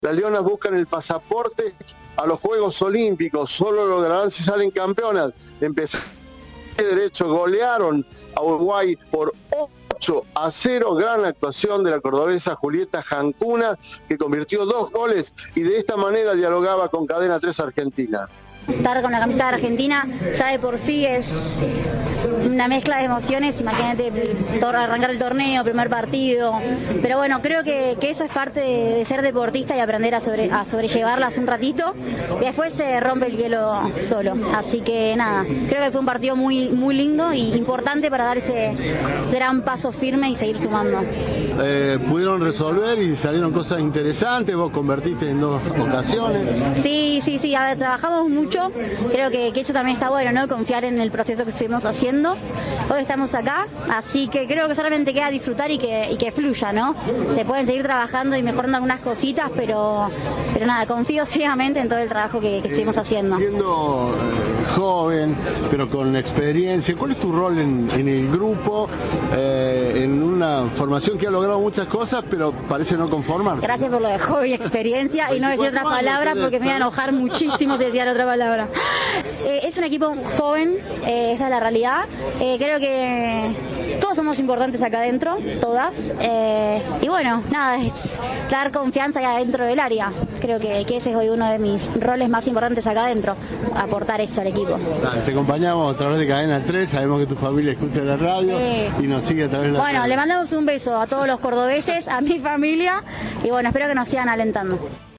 El seleccionado femenino de hockey sobre césped venció 8-0 a su rival en el primer partido del Grupo A del certamen. Tras el encuentro, Cadena 3 habló con Julieta Jankunas.